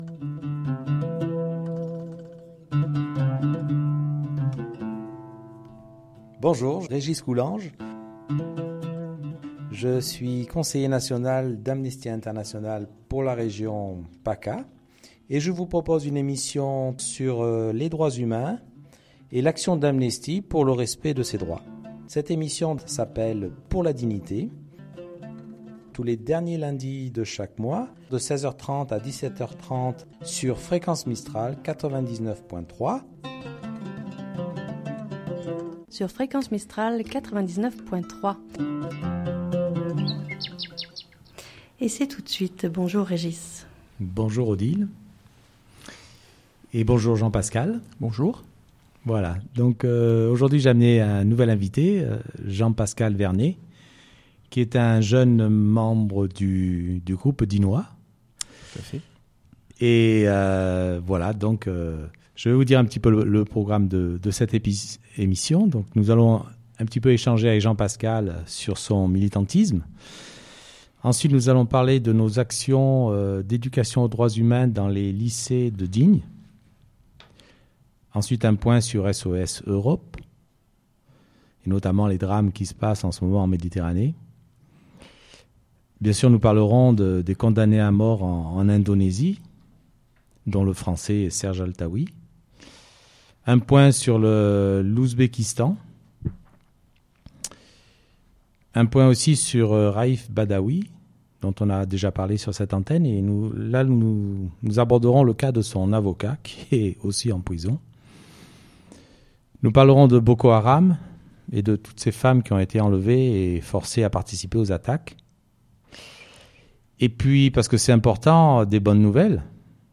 « Pour la Dignité » une émission sur les Droits Humains Émission de fréquence Mistral